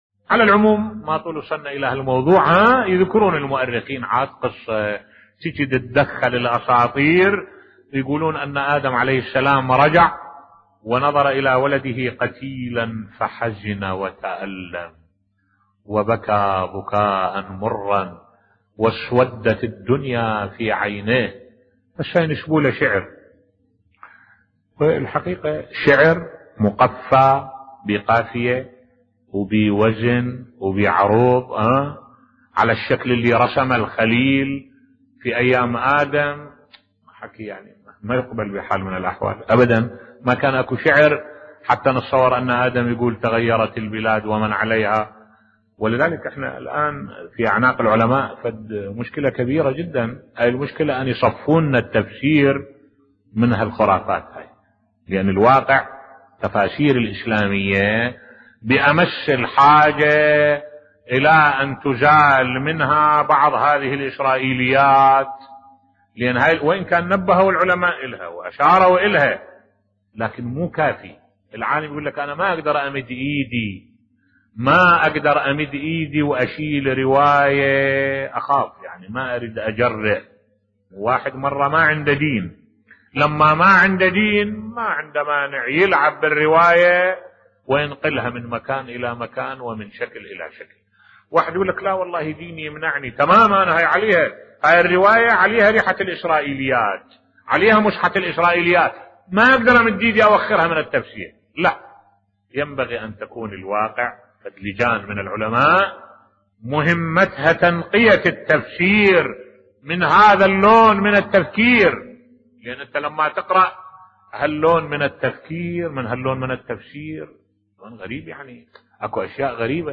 ملف صوتی يدعو علماء الإمامية الى تنقية التراث الشيعي بصوت الشيخ الدكتور أحمد الوائلي